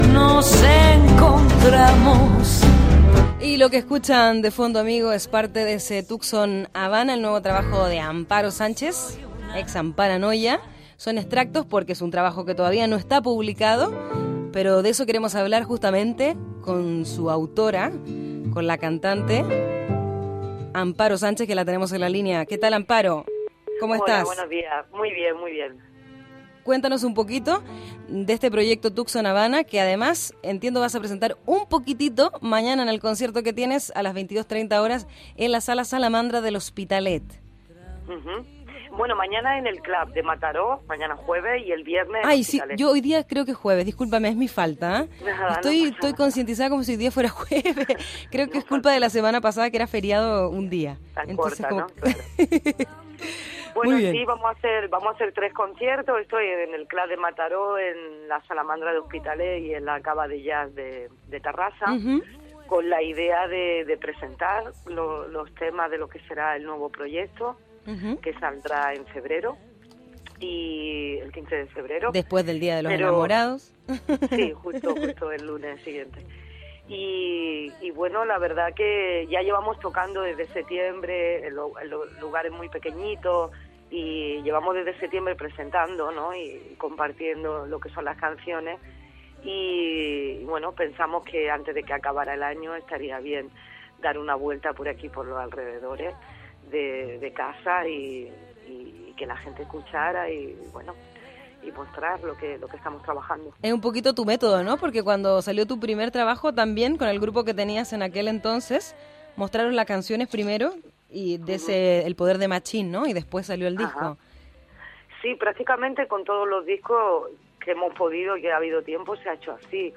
Entrevista telefònica a la cantant Amparo Sánchez "Amparanoia" que a l'endemà actua a Mataró i seguidament a L'Hospitalet de Llobregat i Terrassa
Entreteniment